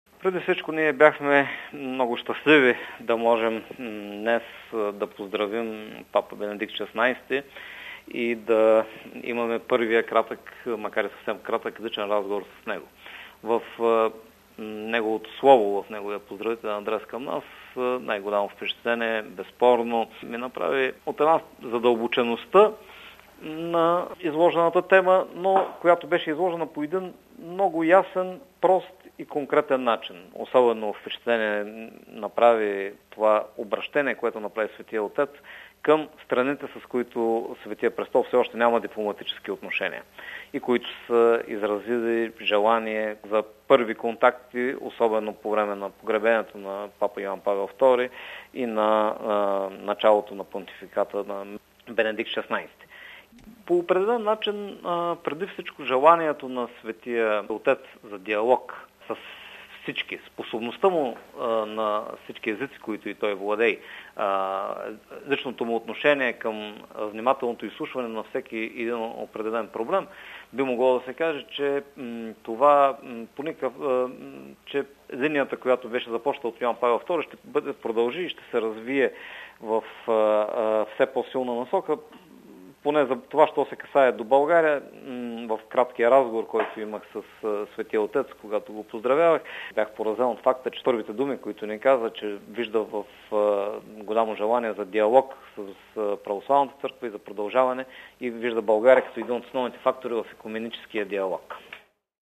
Срещата с Папа Бенедикт ХVІ коментира
настоящия посланик г-н Владимир Градев: RealAudio